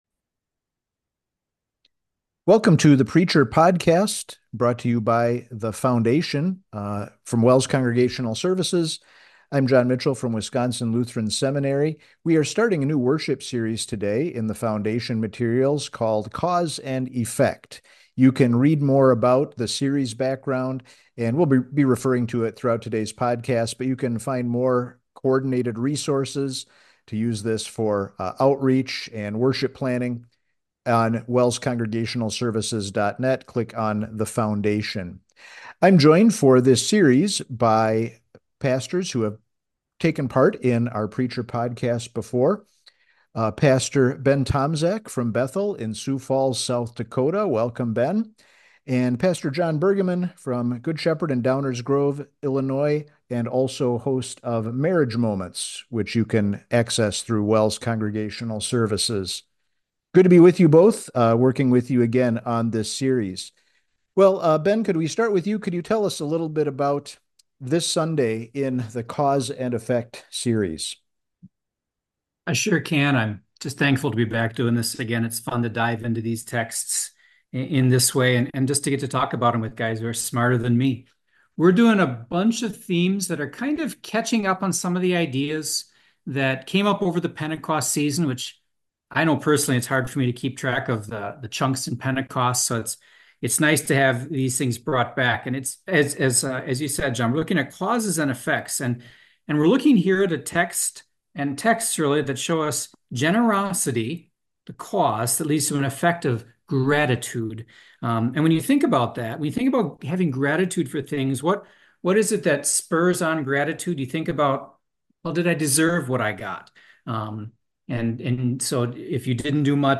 multiple pastors discuss sermon topics for Season After Pentecost